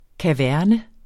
Udtale [ kaˈvæɐ̯nə ]